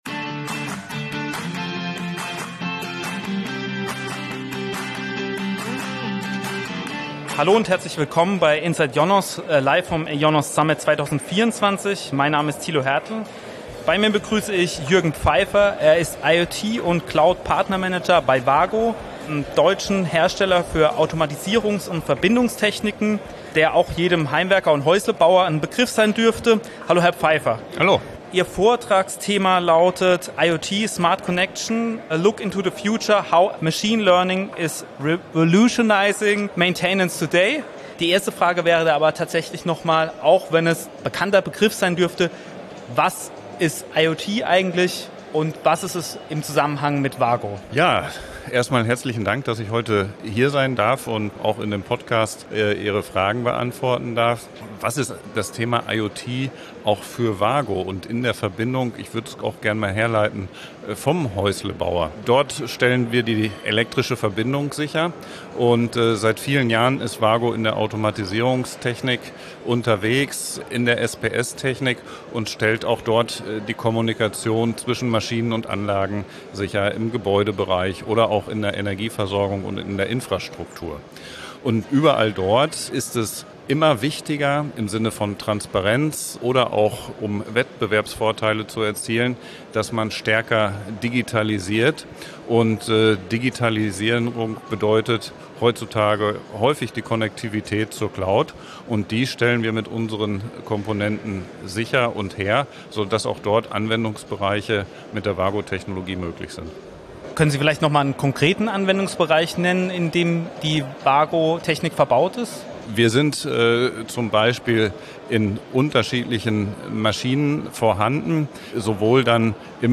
Weitere Podcasts-Episoden zum IONOS Summit 2024